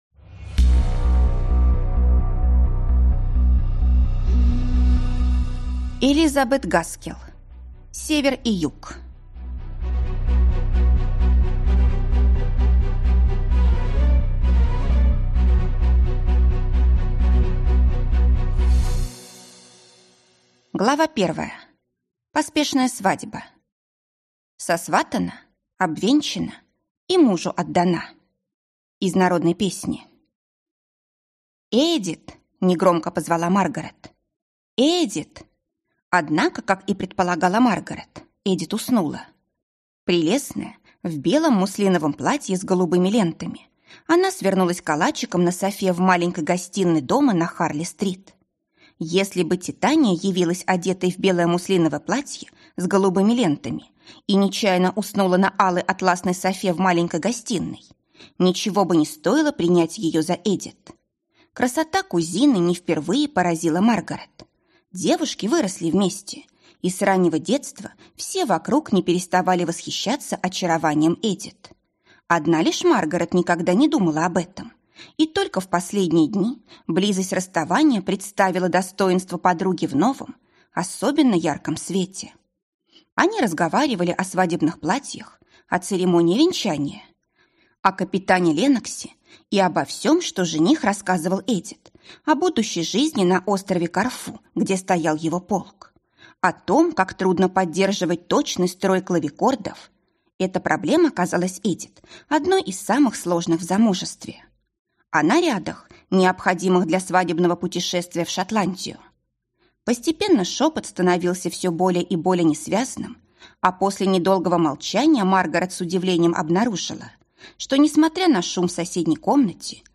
Аудиокнига Север и Юг | Библиотека аудиокниг